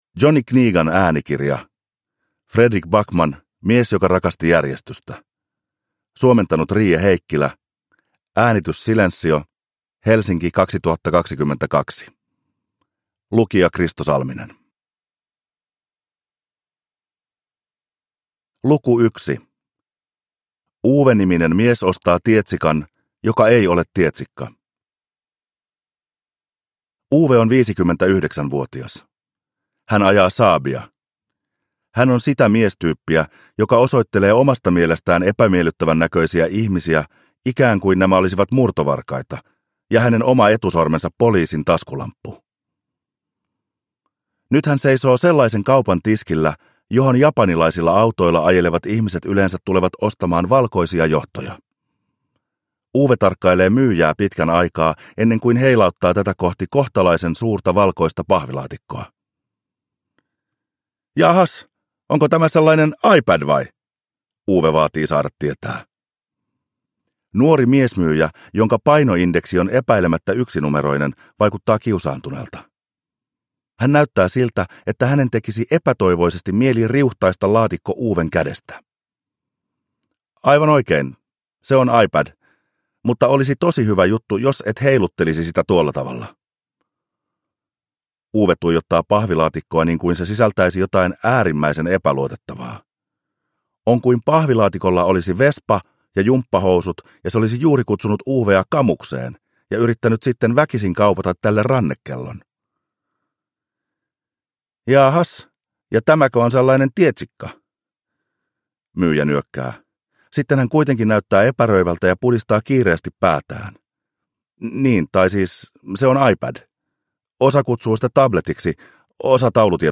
Uppläsare: Kristo Salminen
• Ljudbok